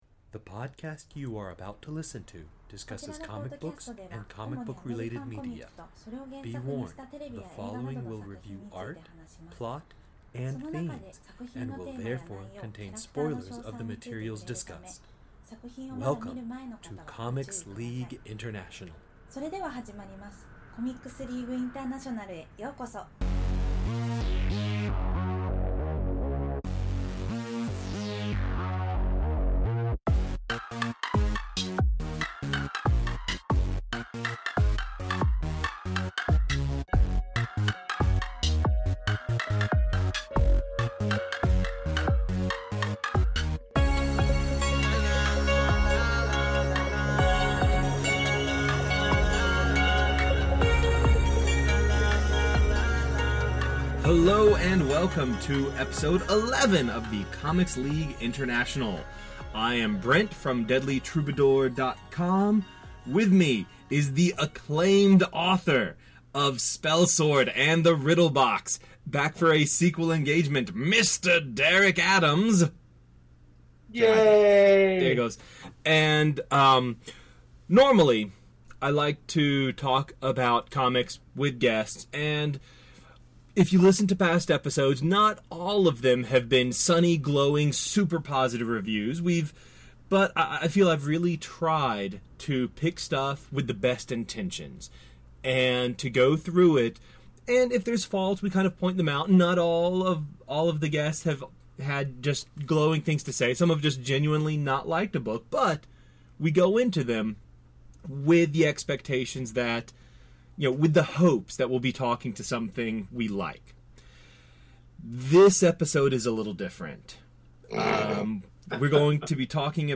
CLI’s intro and outro music